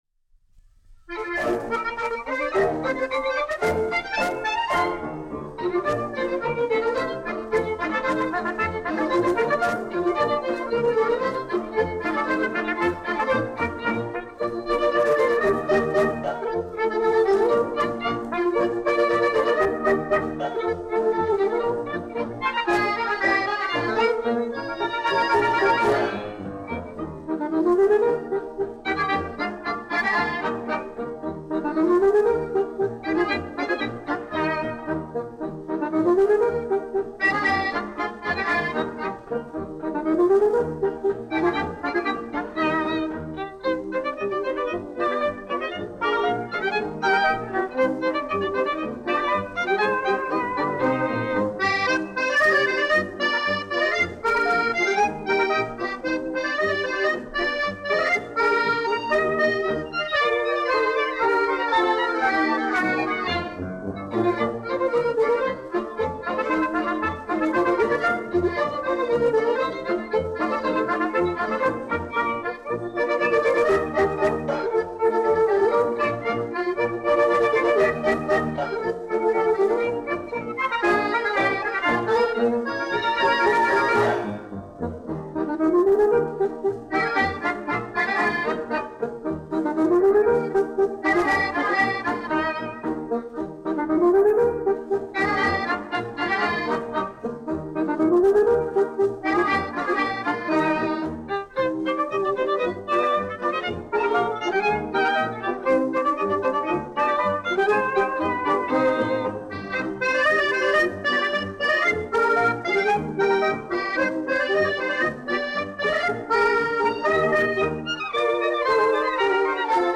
1 skpl. : analogs, 78 apgr/min, mono ; 25 cm
Polkas
Populārā instrumentālā mūzika
Skaņuplate